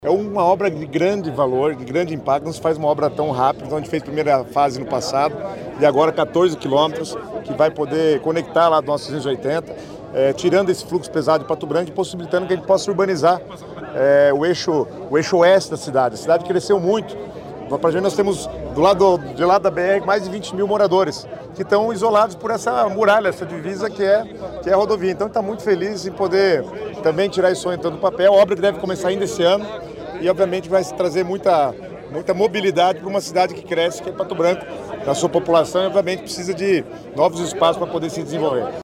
Sonora do secretário das Cidades, Guto Silva, sobre o início das obras da nova etapa do Contorno de Pato Branco